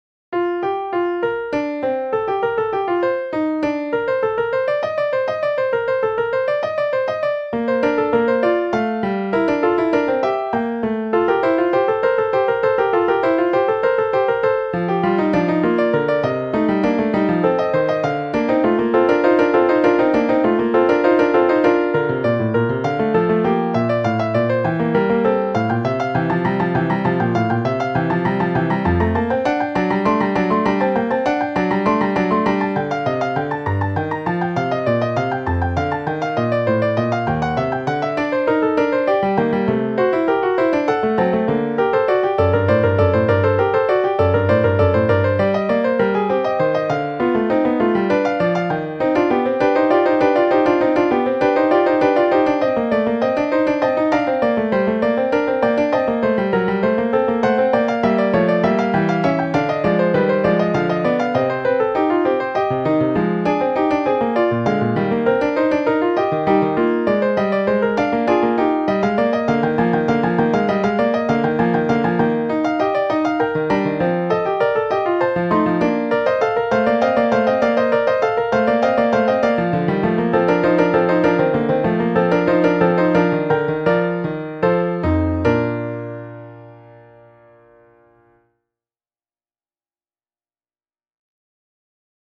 Listen to the Fugue in B-Flat Major here: You can download this as an MP3 here .
Fugue in B Flat Major.mp3